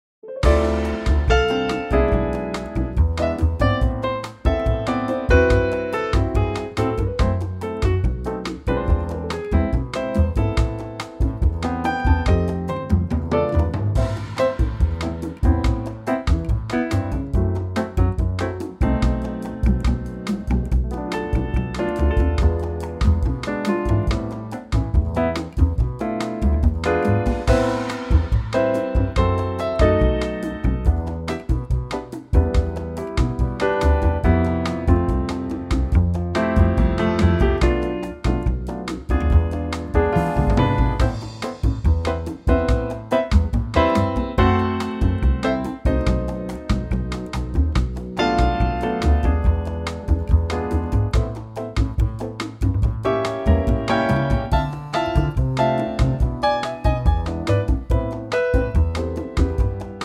key - Am (bridge in A) - vocal range - E to G#
latin gem
in a trio plus percussion arrangement